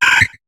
Cri de Tylton dans Pokémon HOME.